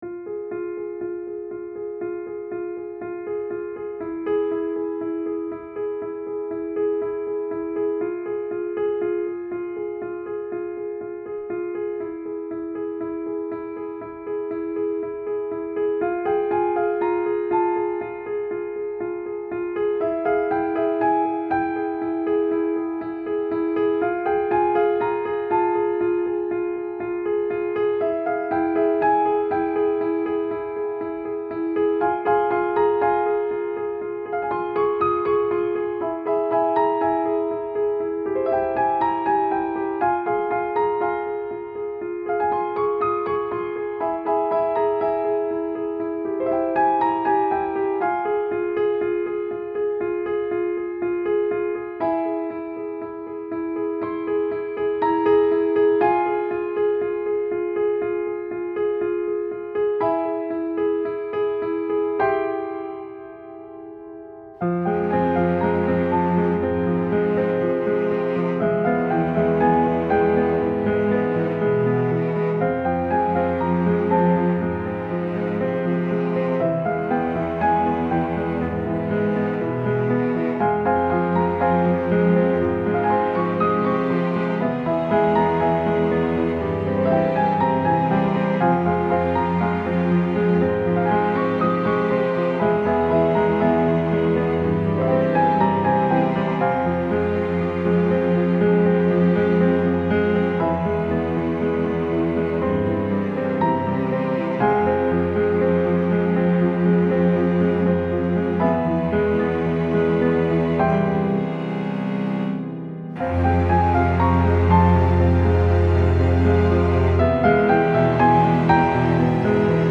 Style Style Soundtrack
Mood Mood Dark, Suspenseful
Featured Featured Piano, Strings